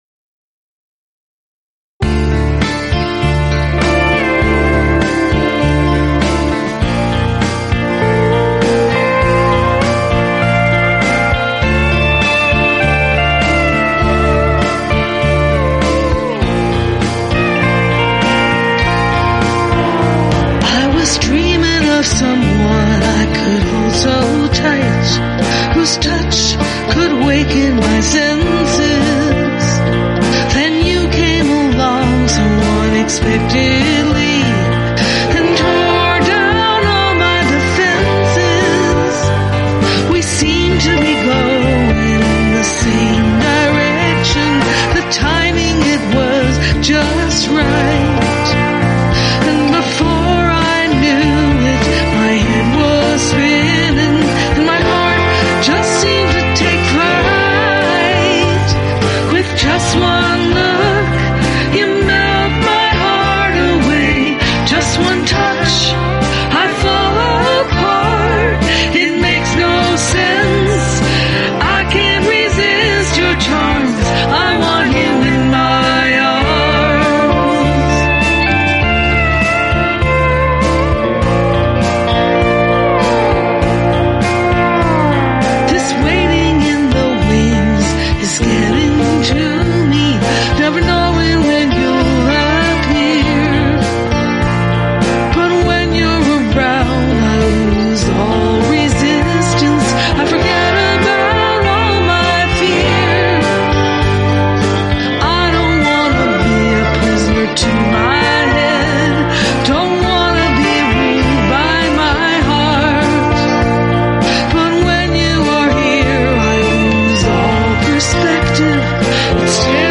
Pedal Steel and Guitars
Recorded, mixed and mastered in Presonus Studio One 4.6 at home Studio in San Luis Obispo, Ca.
Piano, Bass and Drum tracks recorded in GarageBand